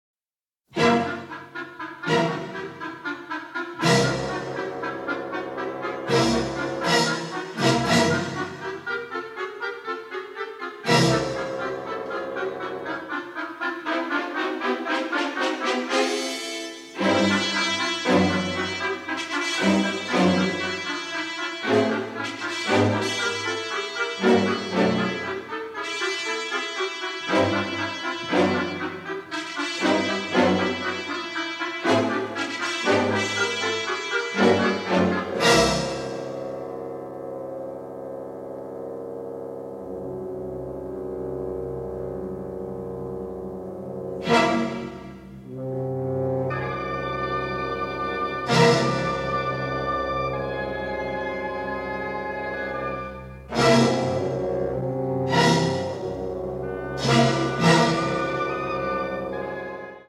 THE FILM SCORE (MONO)